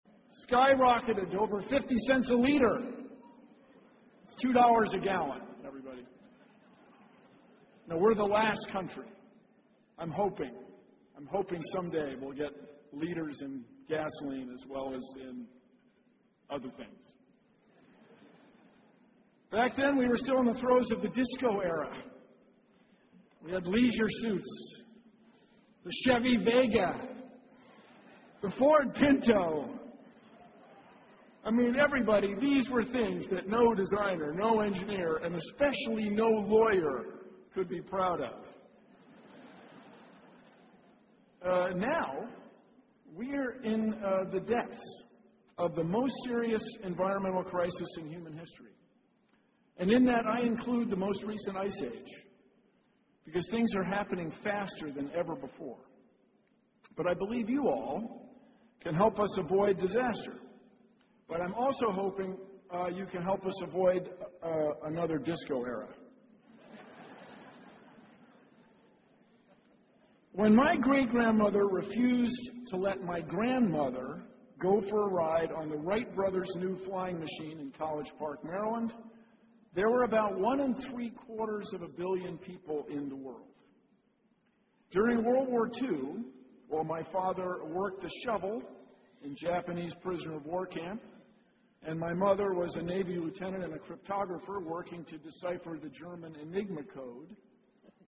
公众人物毕业演讲 第157期:比尔·奈马萨诸塞大学2014(4) 听力文件下载—在线英语听力室